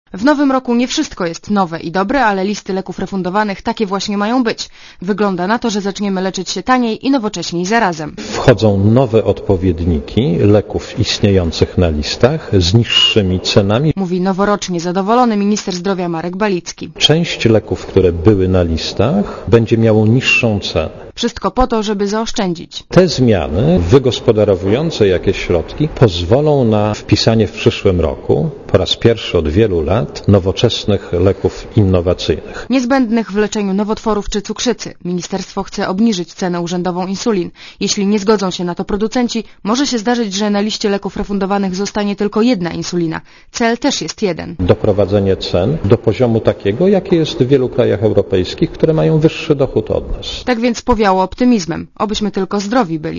Źródło zdjęć: © Archiwum 30.12.2004 17:27 ZAPISZ UDOSTĘPNIJ SKOMENTUJ Relacja reportera Radia ZET